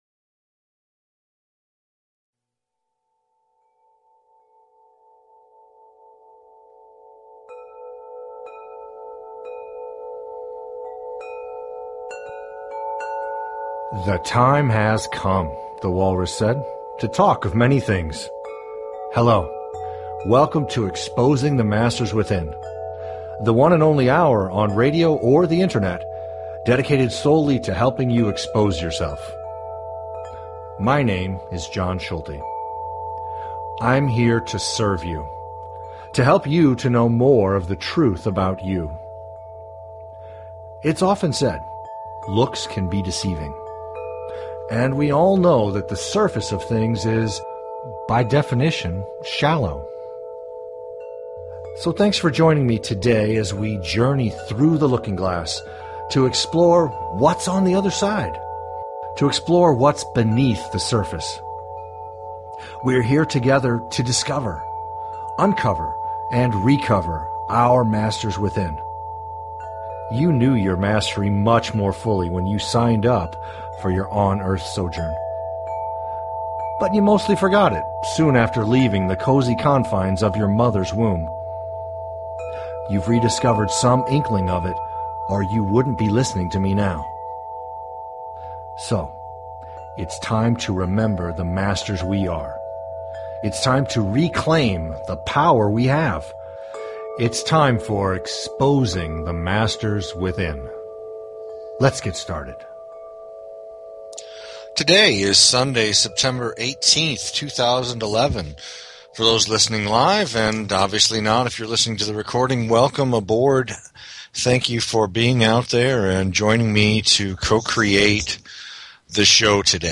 Talk Show Episode, Audio Podcast, Exposing_the_Masters_Within and Courtesy of BBS Radio on , show guests , about , categorized as